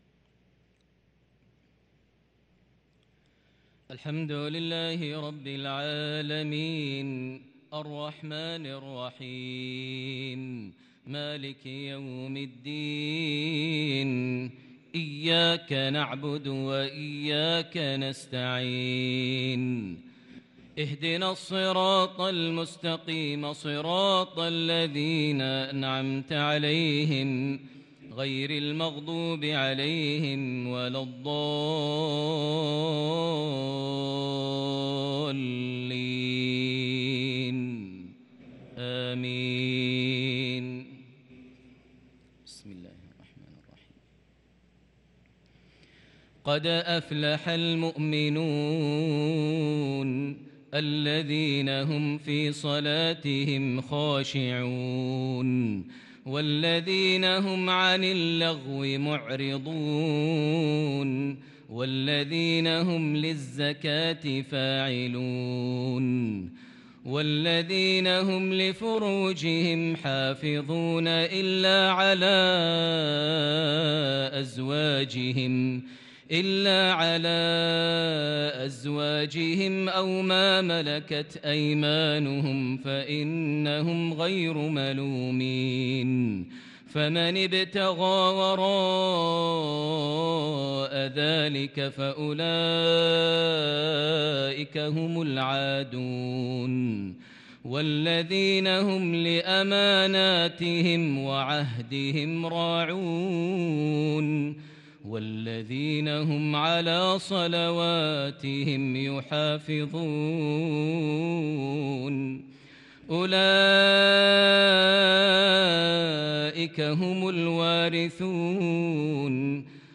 صلاة العشاء للقارئ ماهر المعيقلي 27 ذو القعدة 1443 هـ
تِلَاوَات الْحَرَمَيْن .